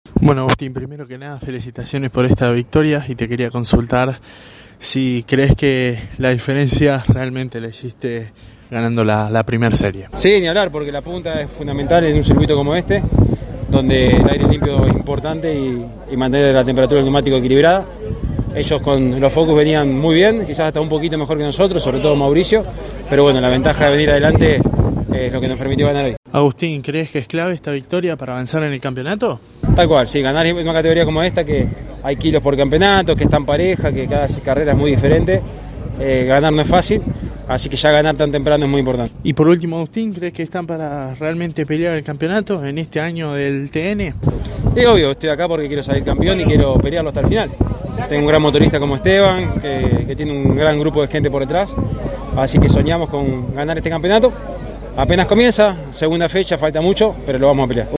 LA PALABRA DE LOS TRES DEL «ESTRADO DE HONOR» DE LA FINAL DE LA CLASE 3 DEL TN EN CÓRDOBA